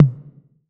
6TOM MID 2.wav